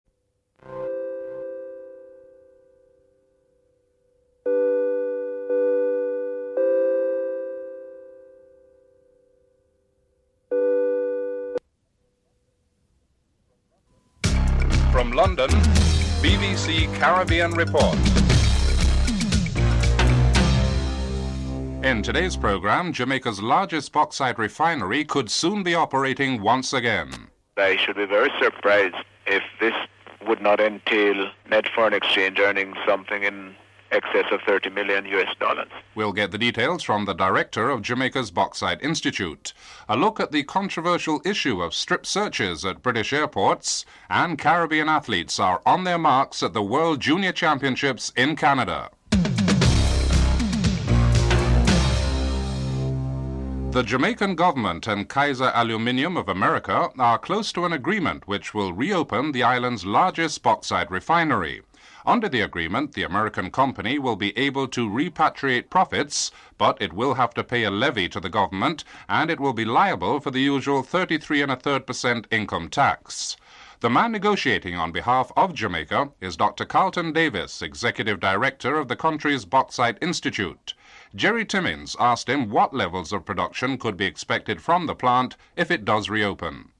The discussion includes the controversial strip searches and “intimate” searches, and the rights of individuals where these types of searches are concerned.